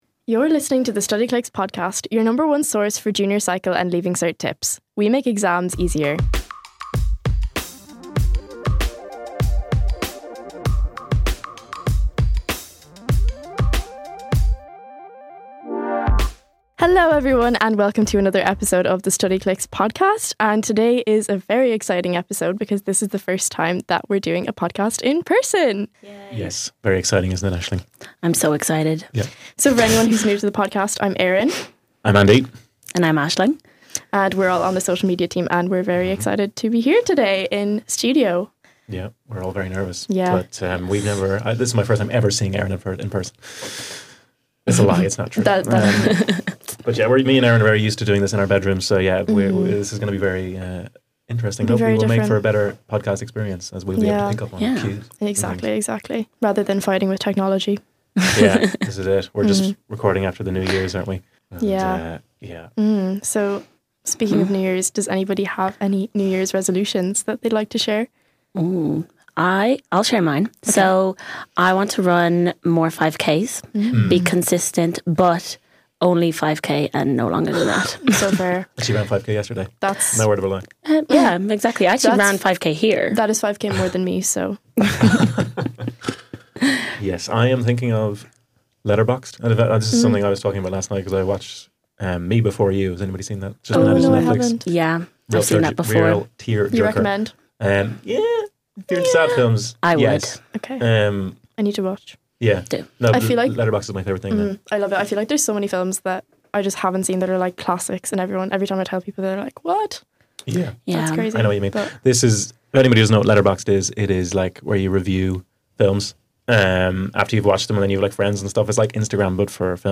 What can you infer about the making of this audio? This episode is a special one as it's our first time recording in-person, so the guys catch up on New Year's resolutions and the TV shows they've been watching before they kick off with their tips.